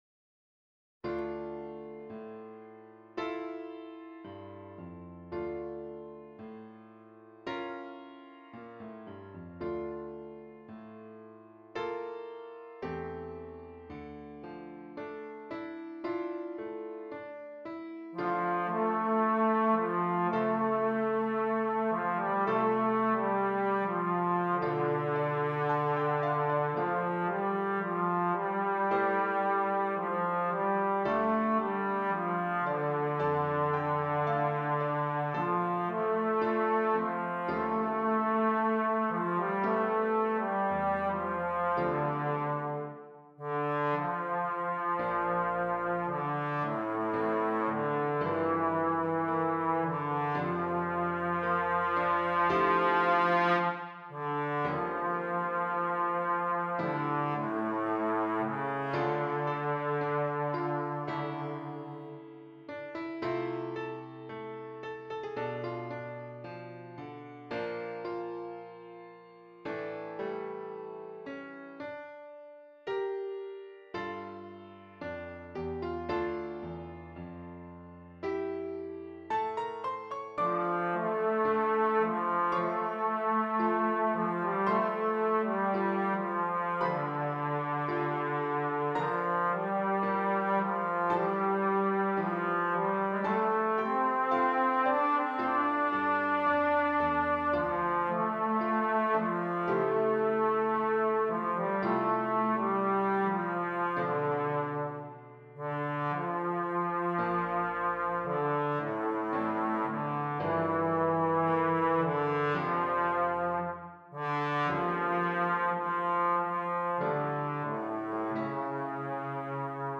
Trombone and Keyboard